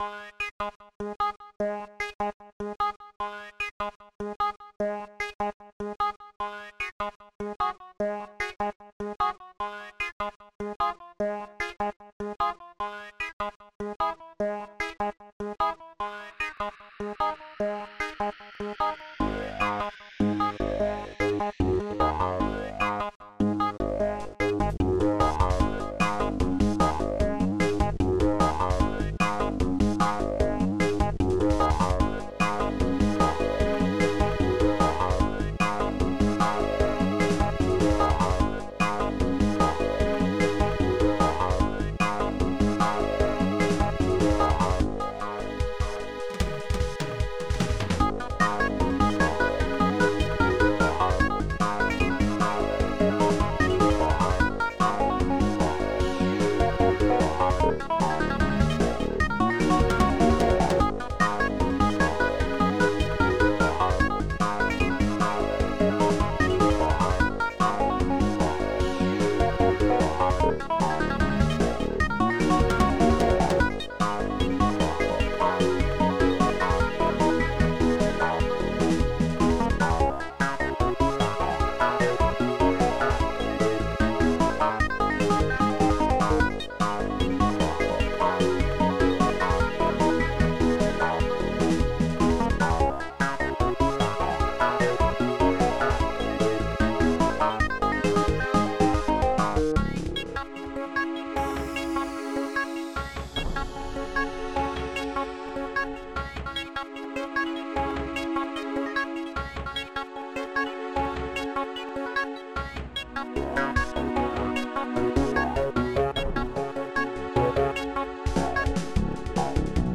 mellow
Digital Symphony Module